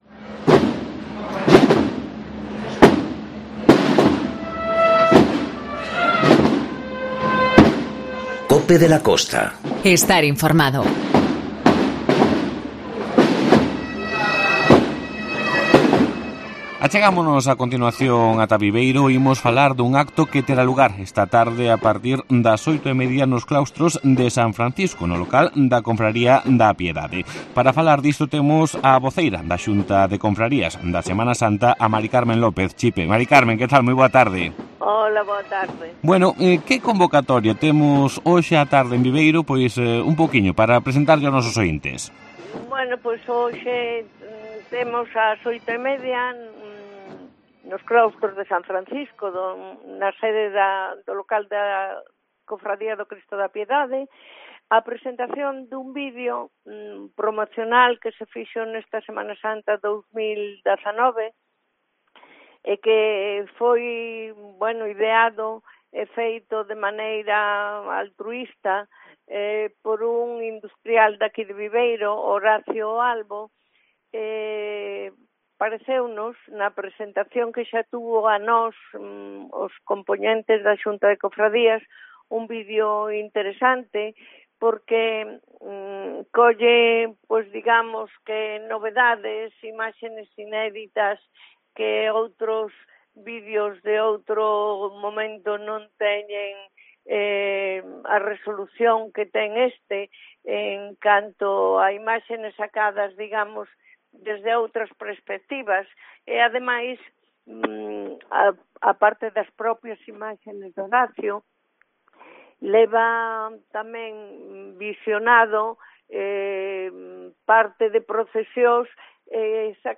COPE de la Costa - Ribadeo - Foz Entrevista